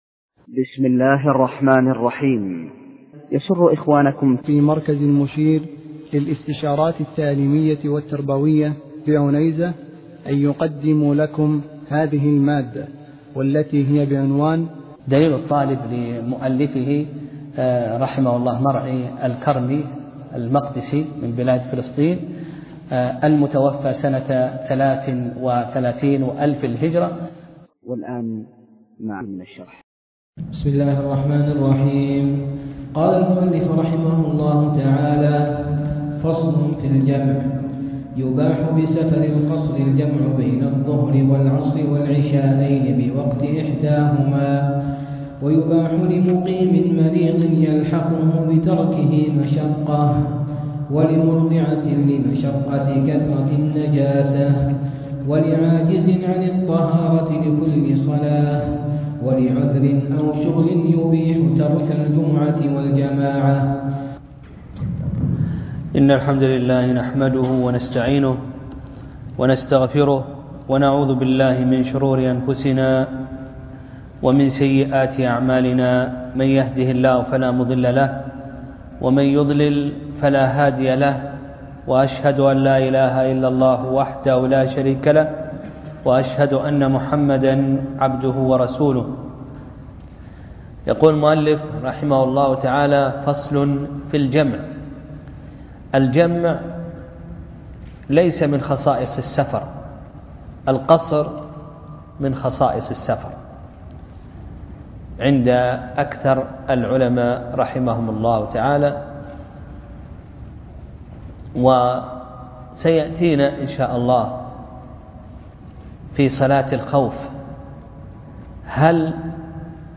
درس (16) : فصل في الجَمع (1)